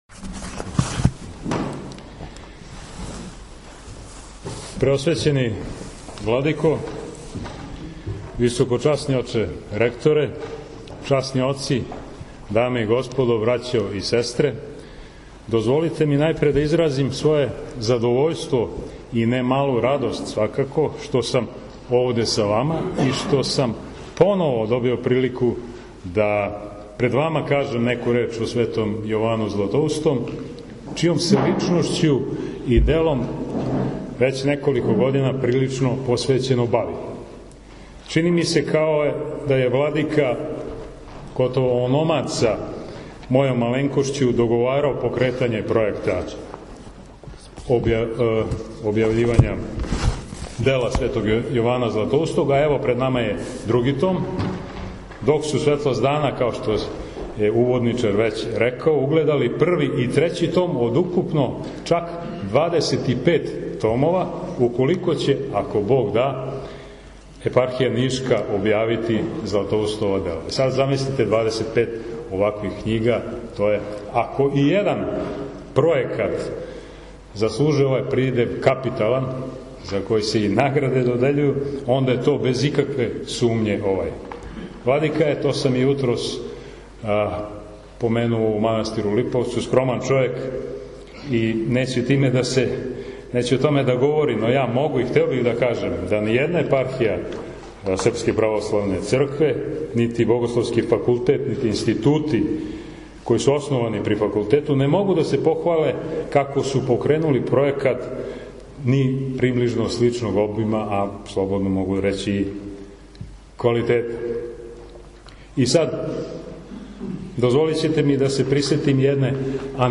Са благословом Његовог Преосвештенства Епископа нишког г. Јована, у Богословији у Нишу 26. новембра 20147. године представљен је други том Сабраних дела Светог Јована Златоустог.
Представљању Сабраних дела поред многобројног верног народа, присуствовали су наставници и ученици Богословије.